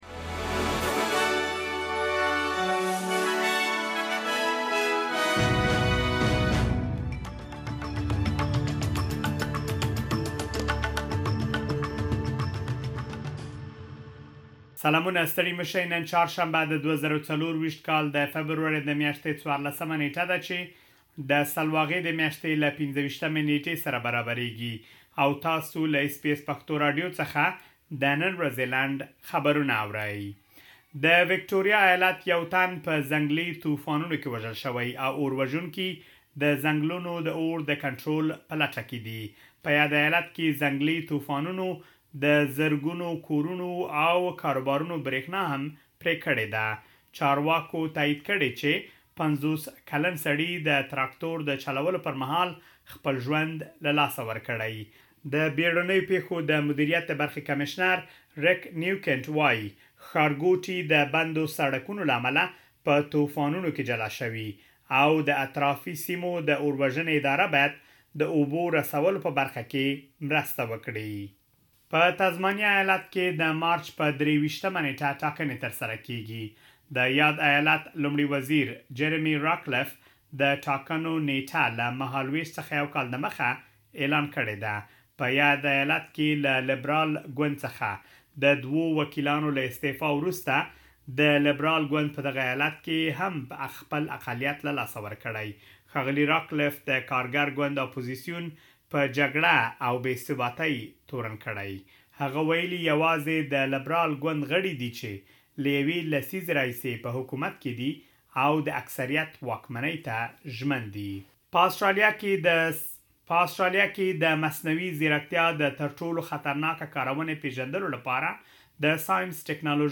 د اس بي اس پښتو راډیو د نن ورځې لنډ خبرونه |۱۴ فبروري ۲۰۲۴